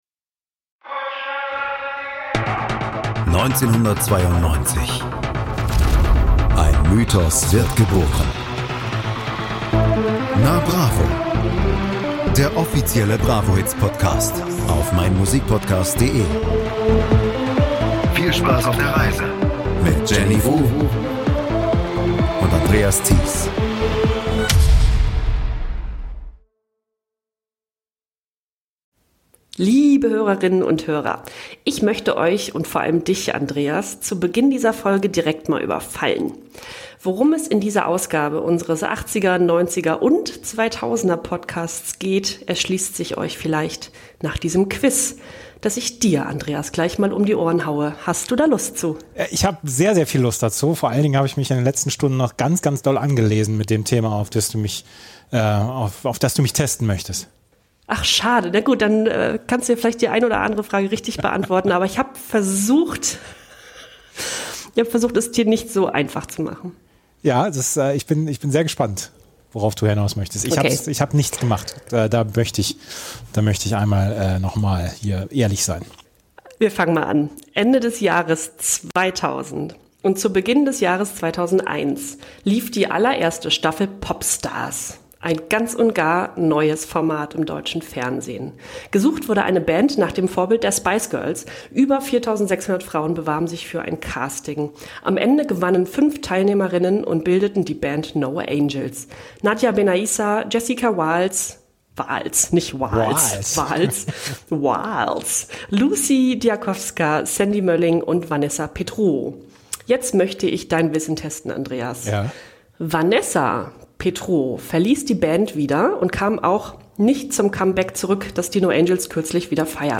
Dass die CD auch nochmal den Umweg über den Big-Brother-Container nimmt, schreckt das Podcast-Duo nicht davor ab, den einen oder anderen Titel wieder anzuspielen.